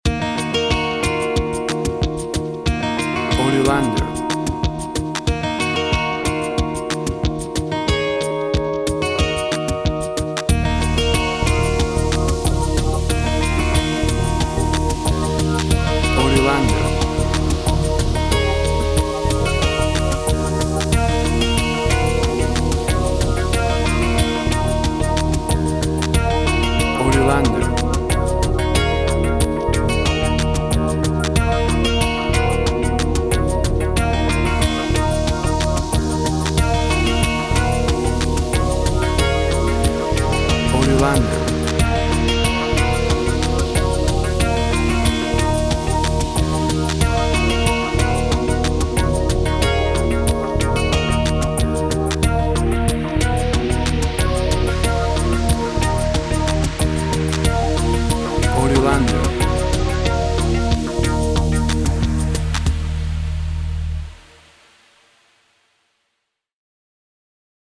WAV Sample Rate 16-Bit Stereo, 44.1 kHz
Tempo (BPM) 92